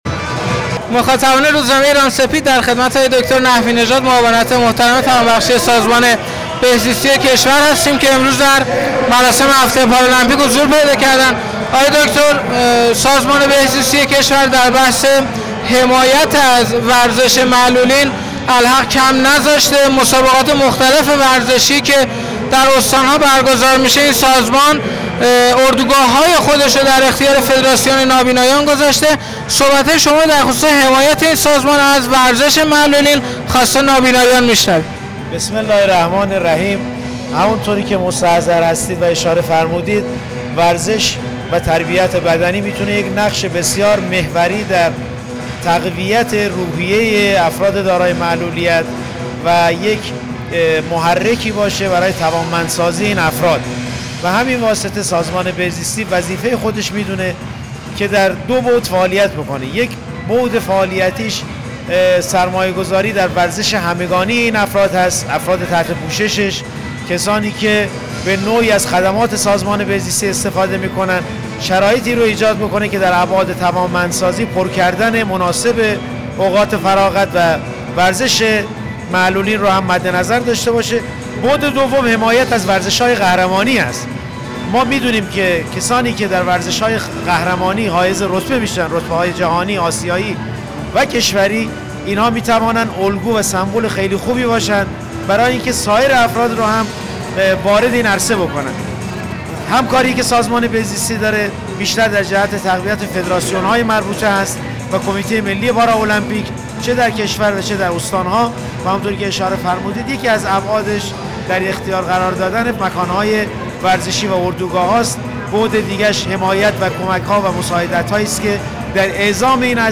در ادامه، گزارشی از این مراسم و در انتها، فایل صوتی با مسئولین حاضر در این همایش را میتوانید دانلود کرده و گوش کنید.
فایل صوتی مصاحبه